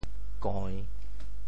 How to say the words 间 in Teochew？
koi~3.mp3